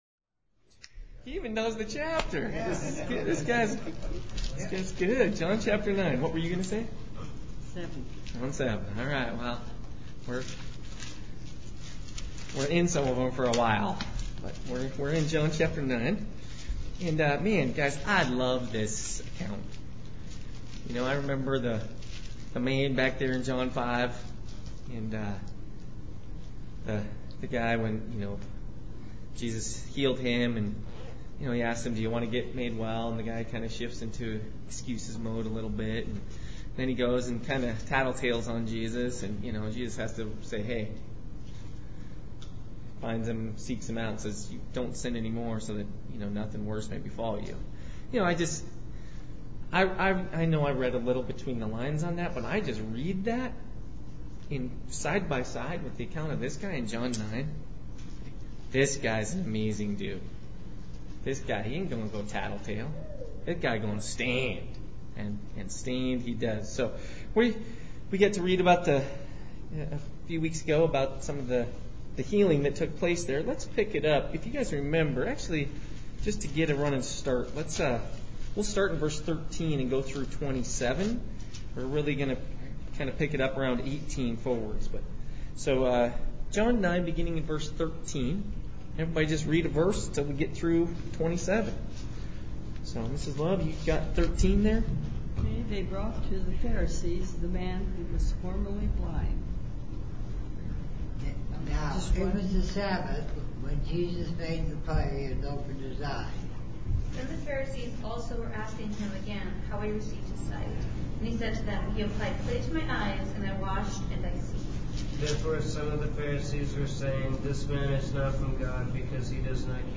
Tonight’s Bible Study session covers more of John 9 beginning in verse 18 where the man who had been born blind and then was abel to see was brought before the Sanhedrin.